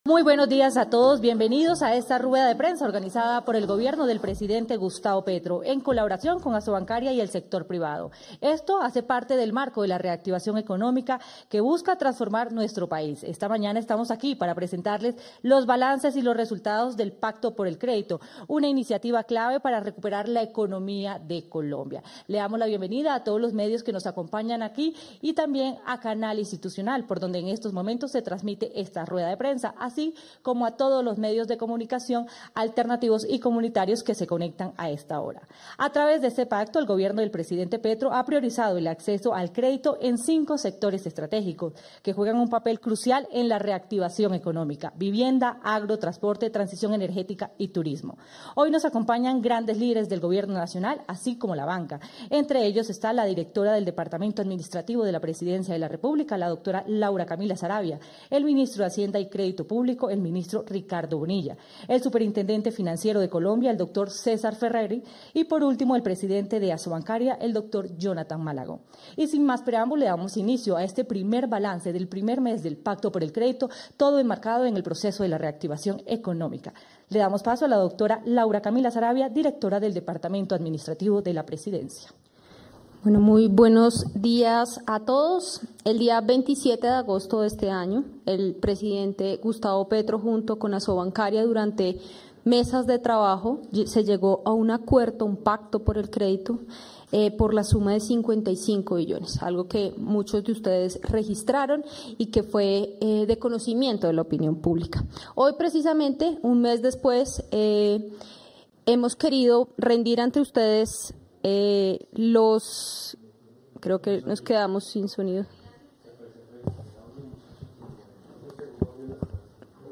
rueda-de-prensa-balance-de-pacto-por-el-credito-1